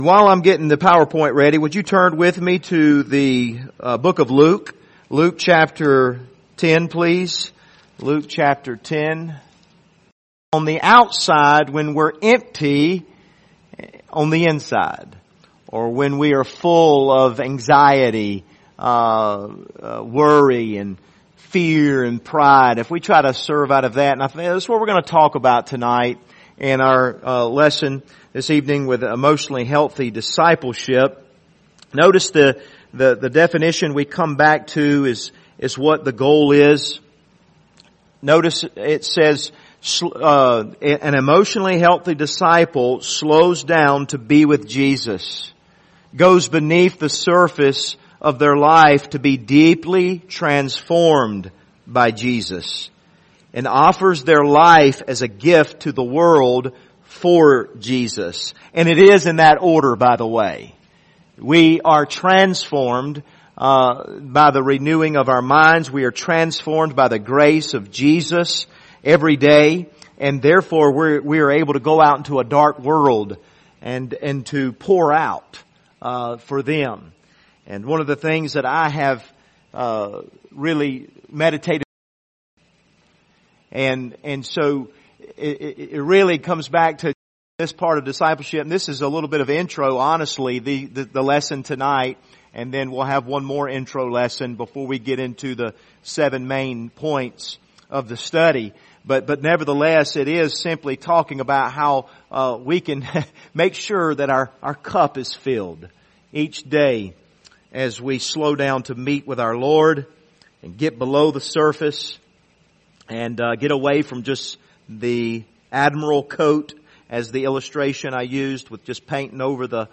Passage: Luke 10:38-42 Service Type: Wednesday Evening